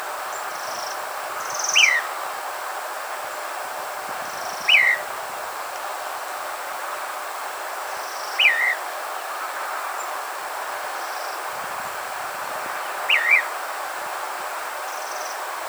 Fio fio – Universidad Católica de Temuco
Fio-fio-Elaenia-albiceps.wav